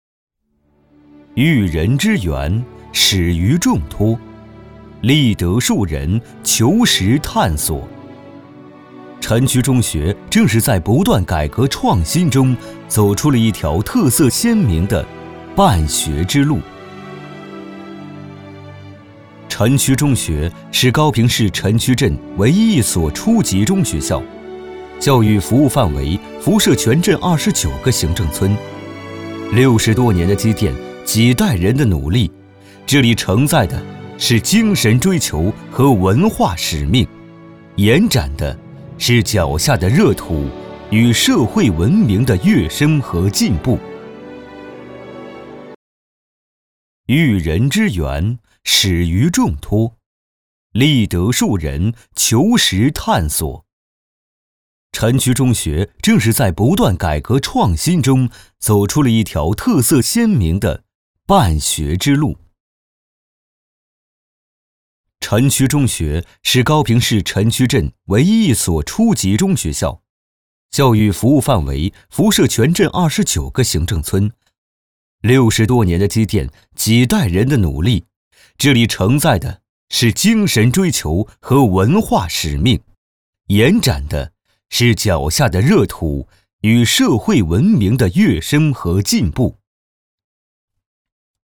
中文男声
• 宣传片
• 大气
• 沉稳